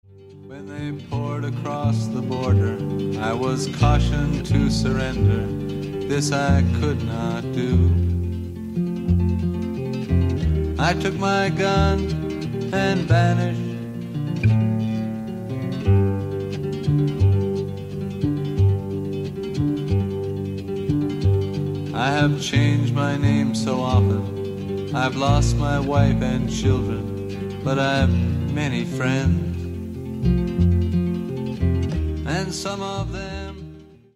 Categoria POP